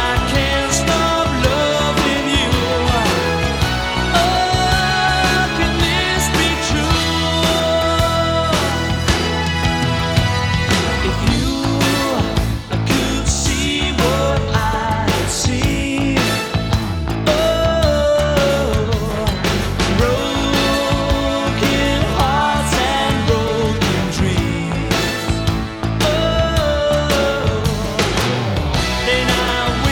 • Rock
The track runs at 110 BPM and is in the key of A major.